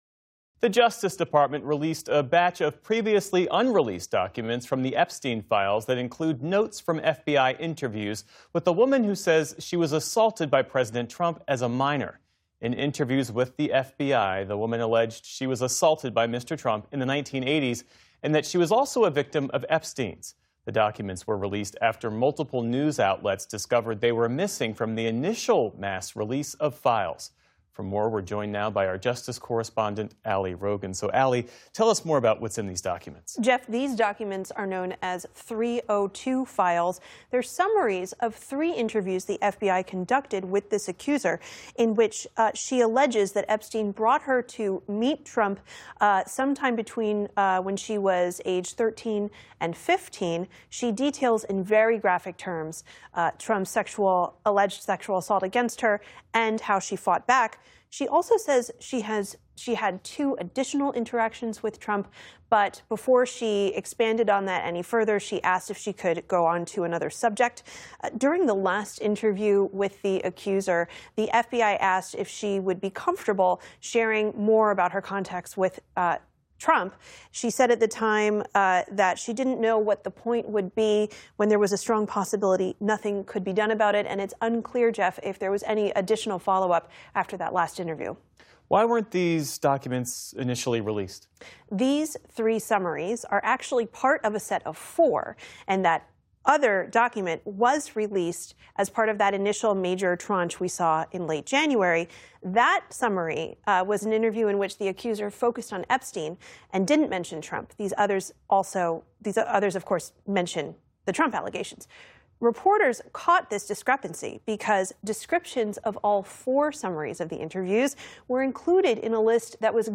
Justice correspondent